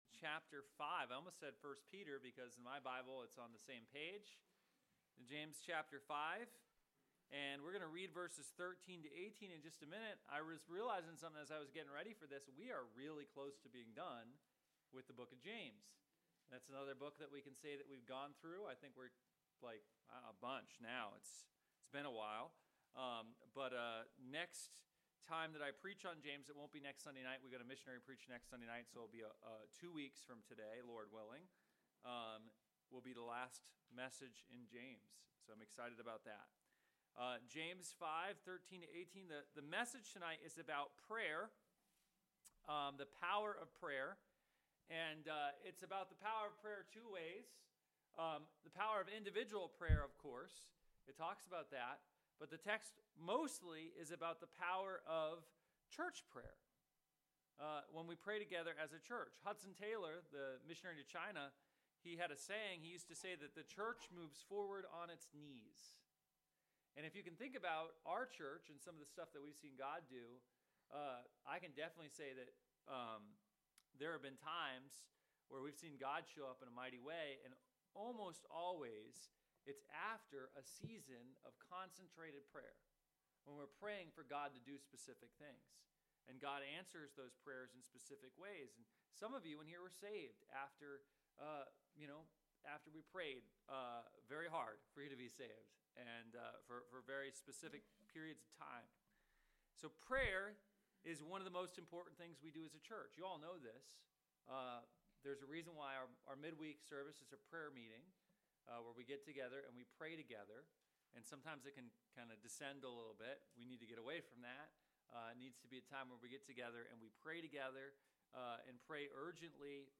Sermons from Bible Baptist Church